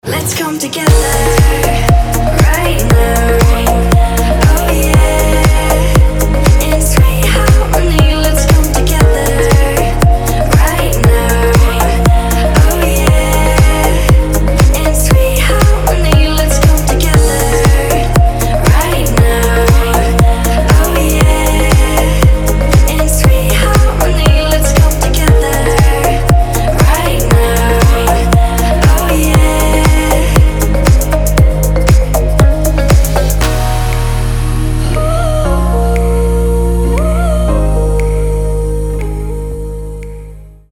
• Качество: 320, Stereo
house